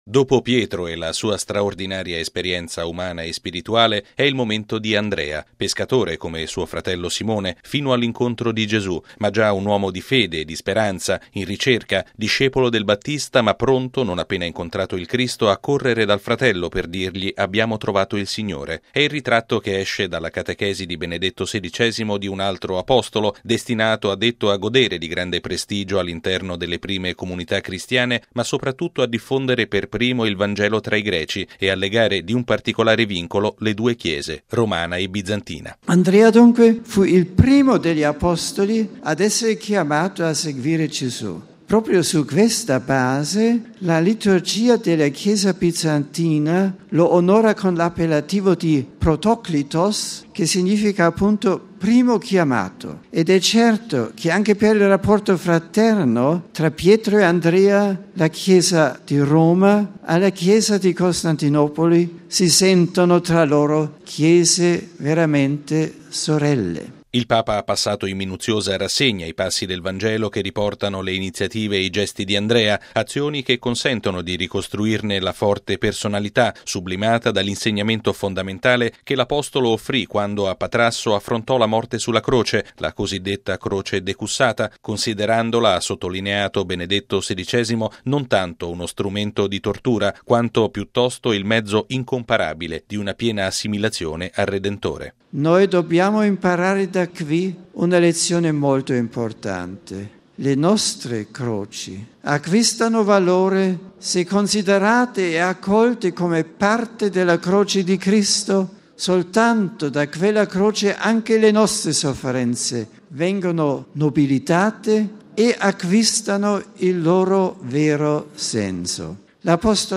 E’ questo l’insegnamento centrale di Benedetto XVI che emerge dall’udienza generale di oggi, svoltasi in una Piazza San Pietro gremita da oltre 30 mila persone. L’udienza si è conclusa con l’invito del Papa alla processione del Corpus Domini, in programma domani per le vie del centro di Roma dopo la Messa a San Giovanni in Laterano.